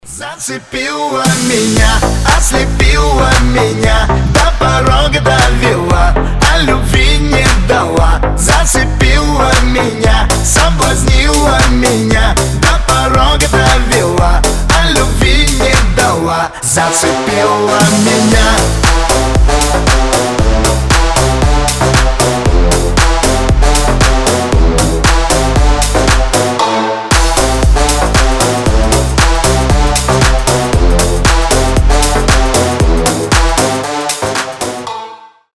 на русском на девушку про любовь веселые